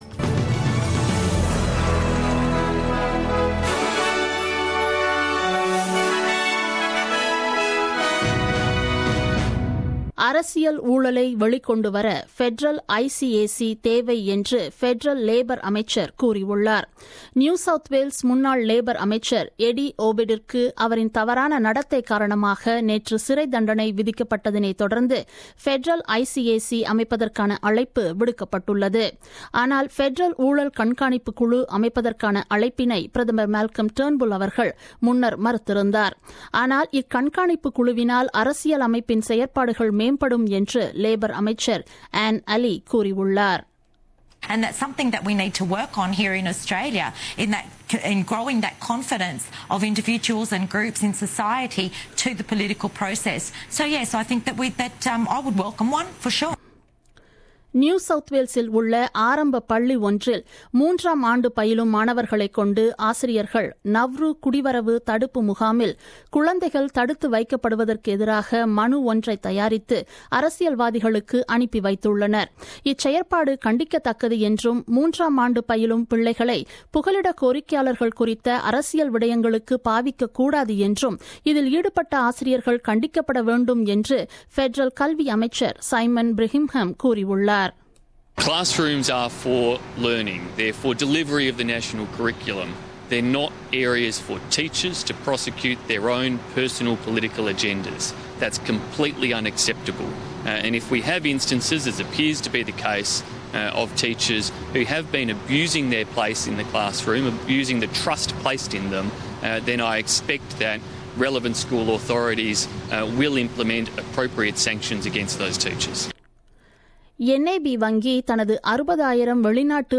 The news bulletin broadcasted on 16 Dec 2016 at 8pm.